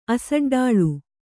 ♪ asaḍāḷu